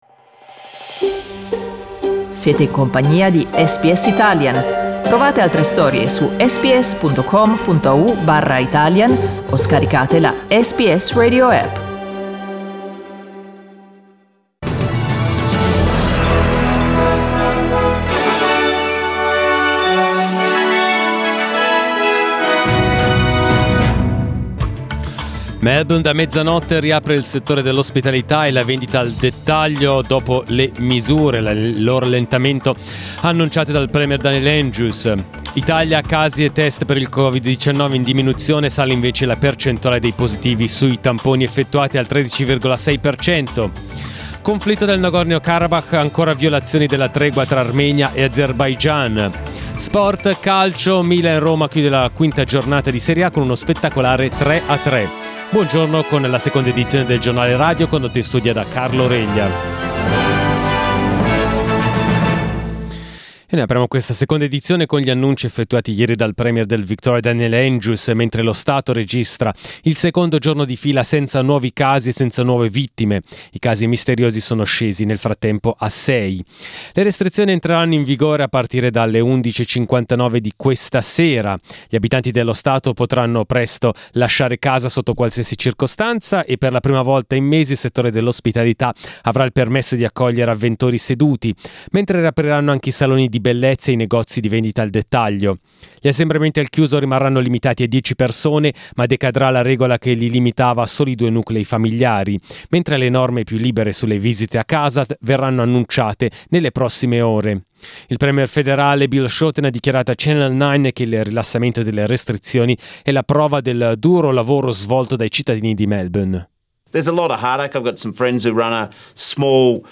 Our news bulletin in Italian.